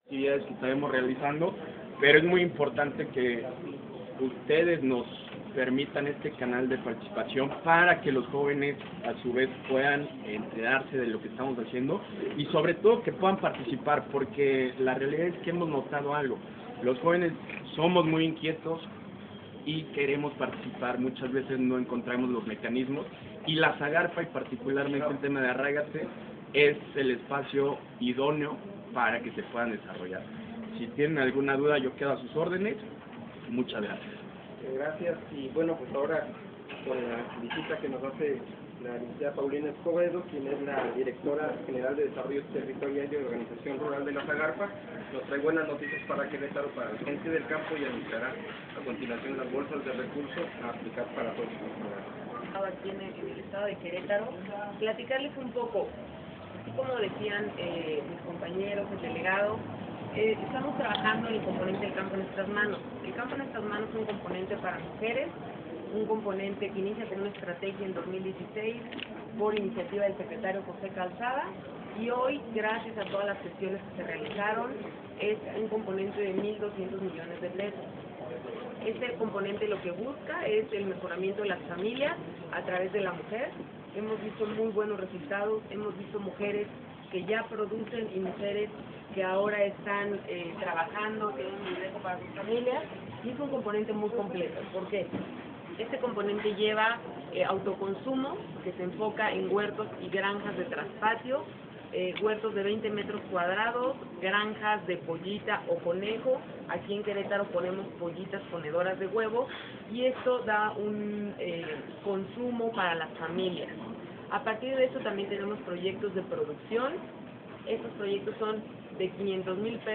Rueda de Prensa SAGARPA III